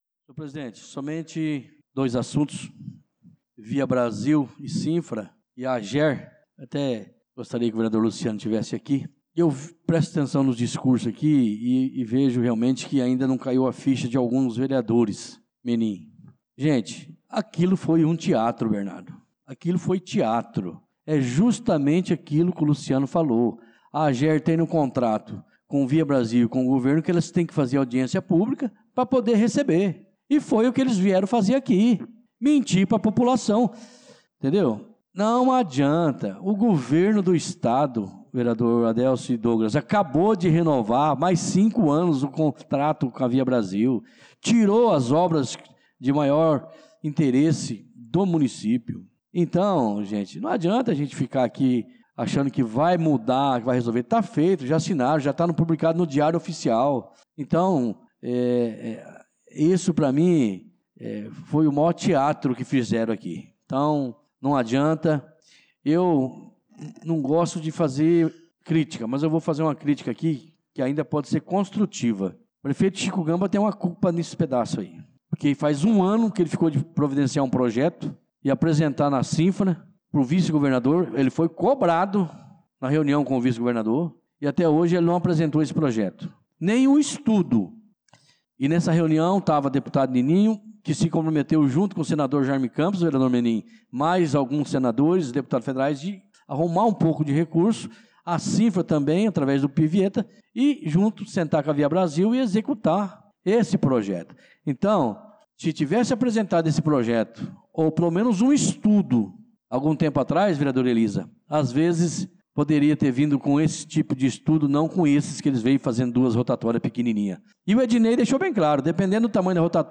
Pronunciamento do vereador Tuti na Sessão Ordinária do dia 25/03/2025